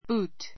boot búːt ブ ー ト